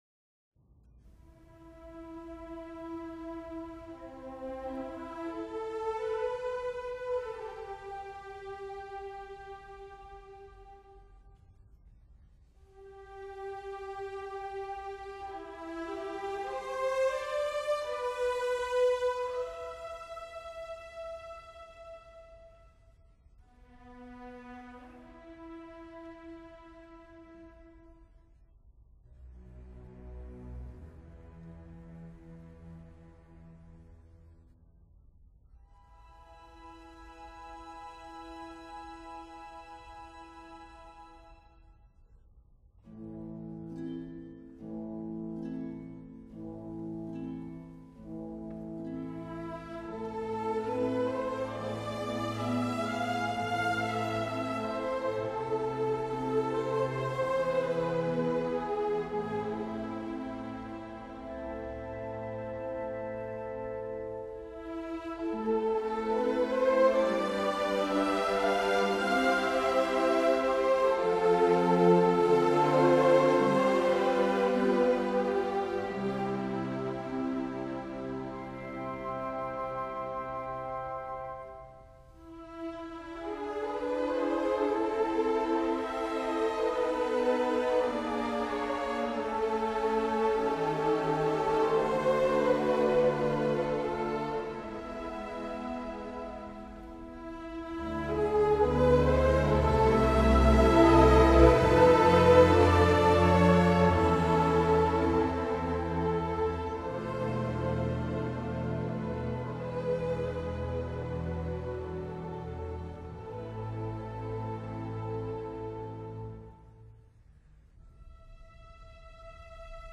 编入组曲的这首乐曲，经作者改编为纯器乐曲，去掉了原来的歌唱声部。 乐曲的旋律十分优美，荡漾着哀愁的第一主题和洋溢着希望的第二主题形成鲜明的对比，是整部组曲中的名篇，也是格里格最成功的创作之一。